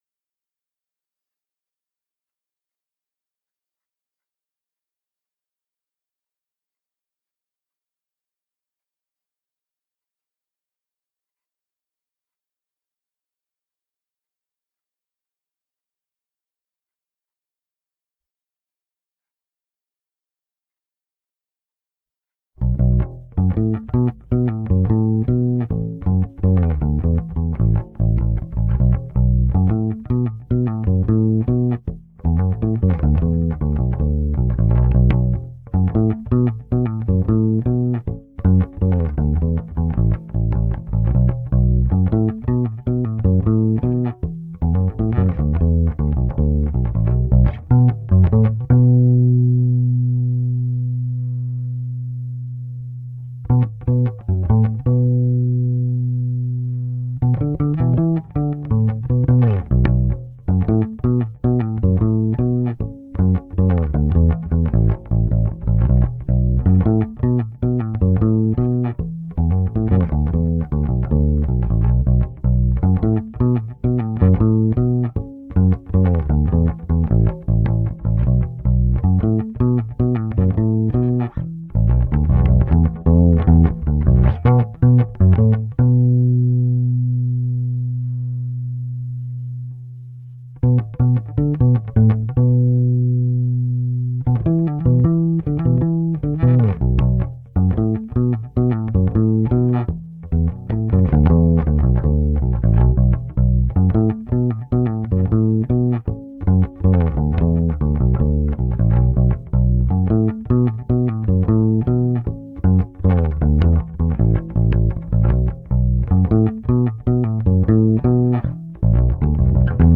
nur bass